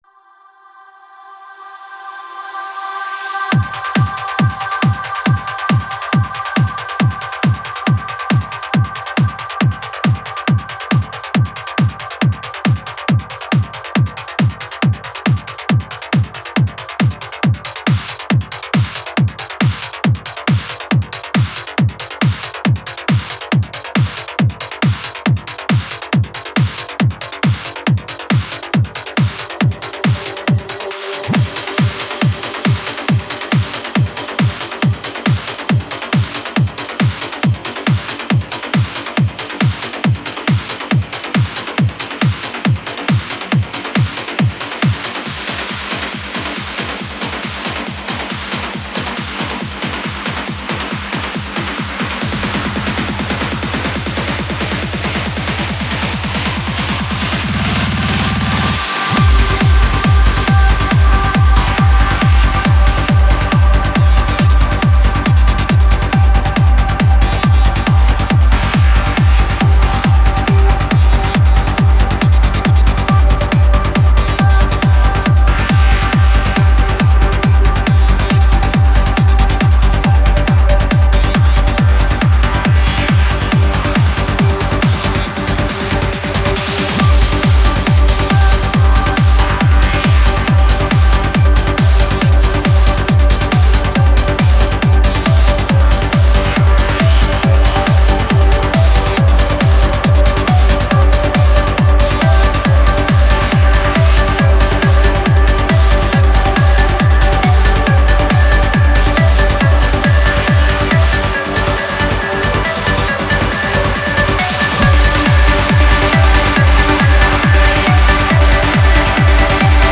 Стиль: Trance / Uplifting Trance